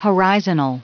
Prononciation du mot horizonal en anglais (fichier audio)
Prononciation du mot : horizonal